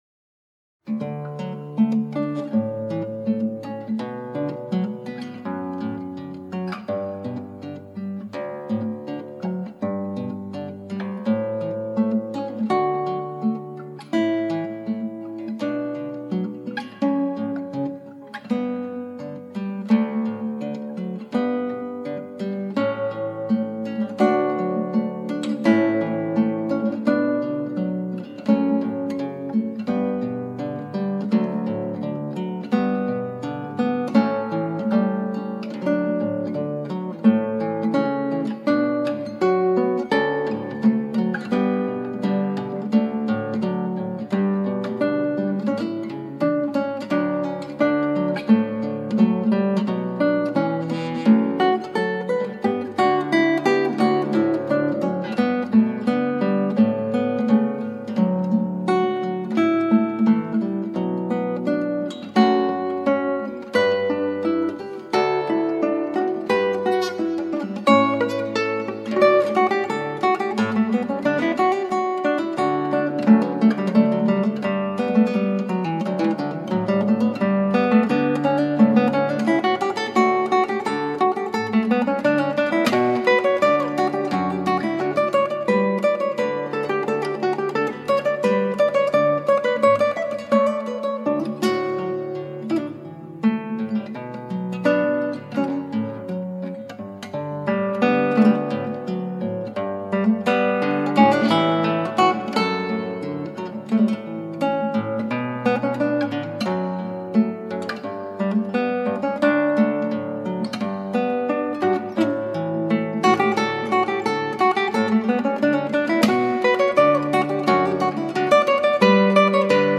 クラシックギター 「手強い・・・」 - 「カノン」Canon
ギターの自演をストリーミングで提供
ほんとはかなり長い曲なんですが、助長なんで短くまとめたヤツを・・・。